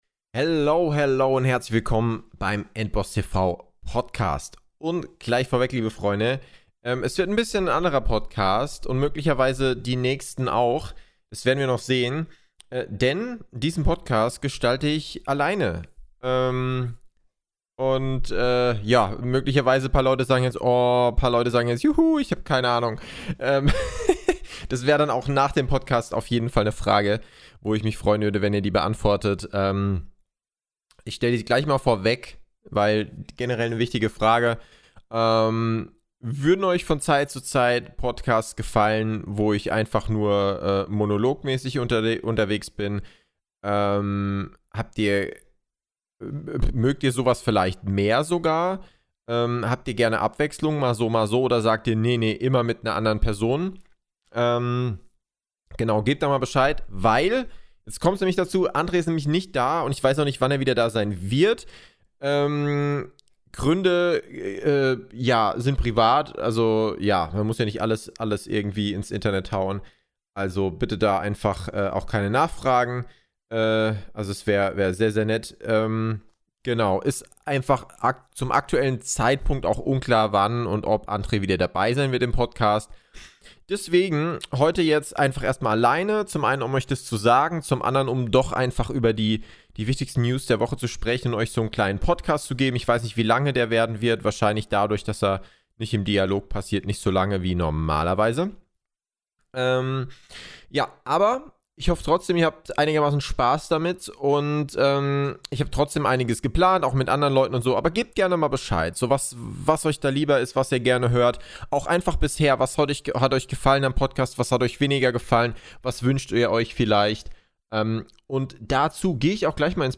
Deswegen gibt es heute einen kürzeren Monolog-Cast mit mir und… mir. Warum wird in der Folge geklärt, genauso wie die News aus den Bereichen Gaming, Filme und Serien und mehr.